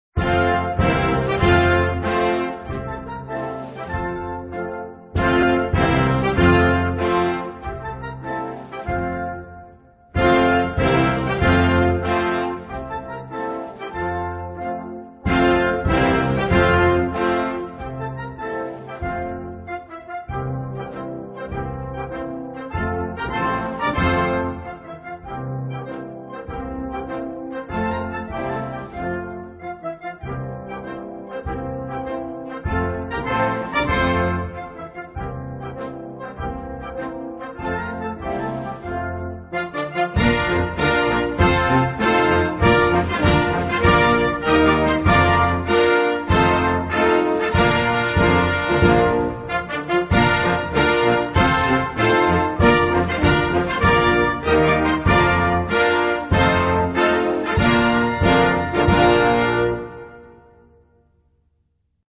國歌